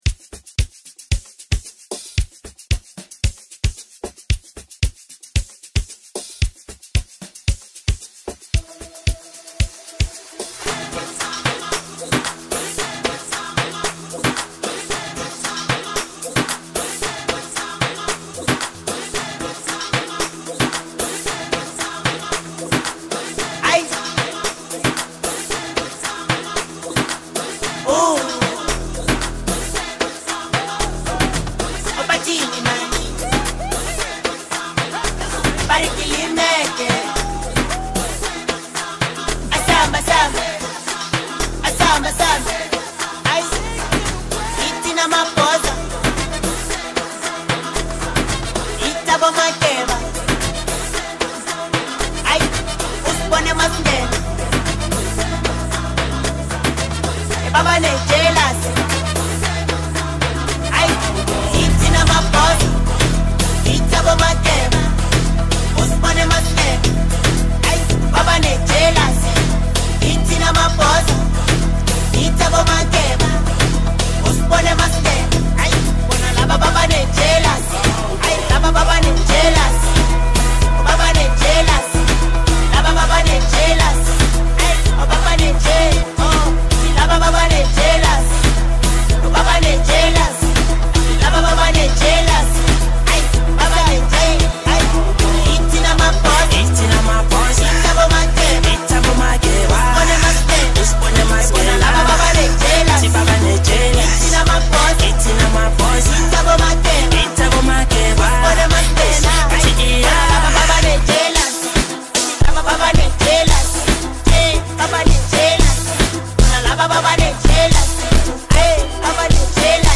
African Music